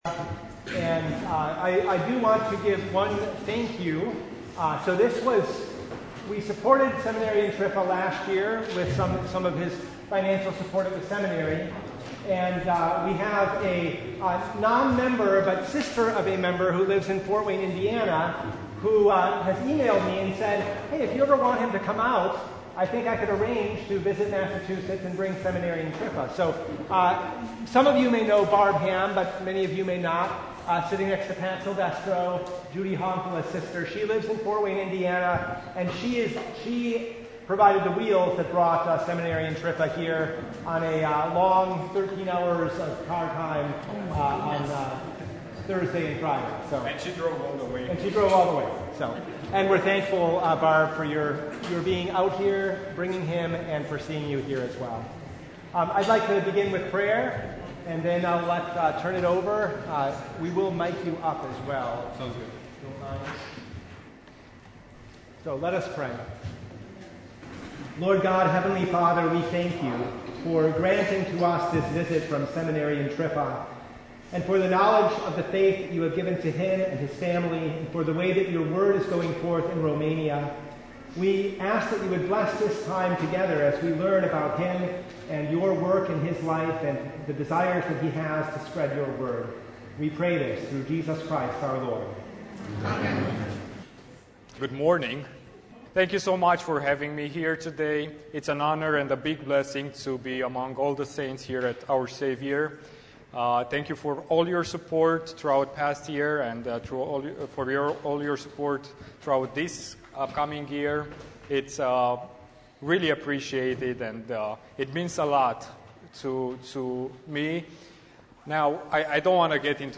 Service Type: Bible Hour